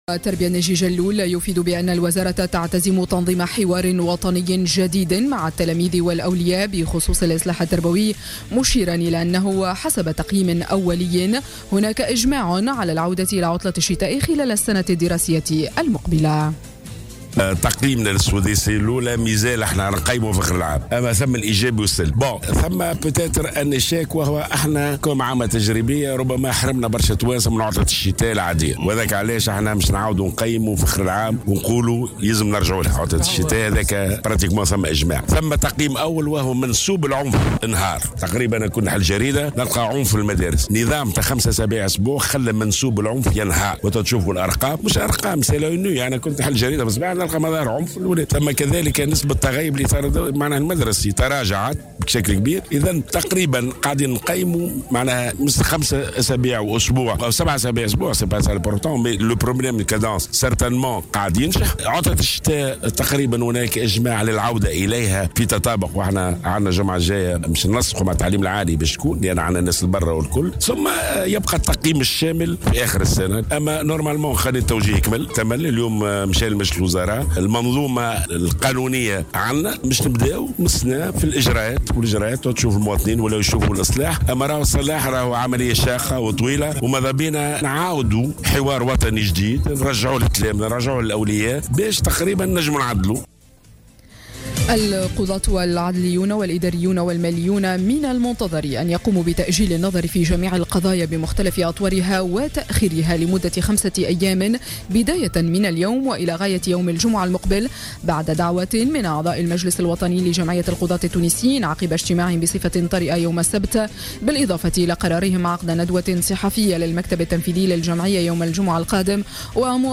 نشرة أخبار السابعة صباحا ليوم الاثنين 9 جانفي 2017